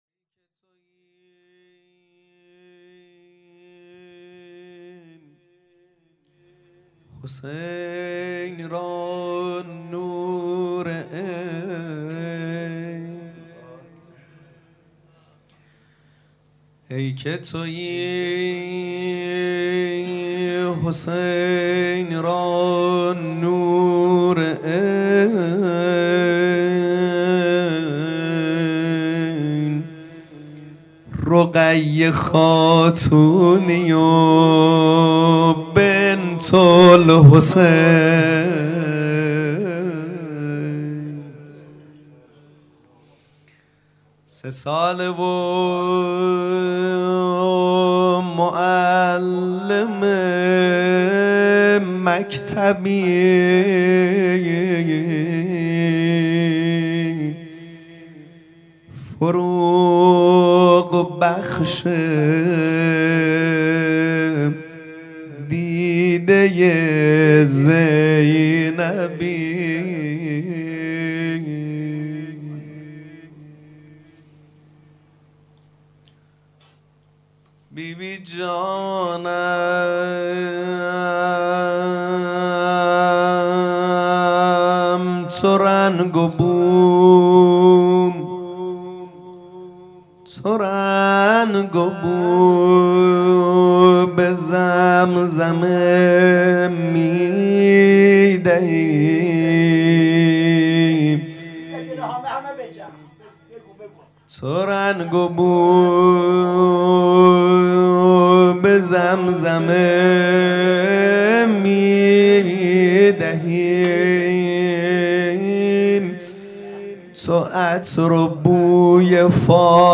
شب بیست و پنجم رمضان 20/تیر/94 :: هیئت رایة الرضا علیه السلام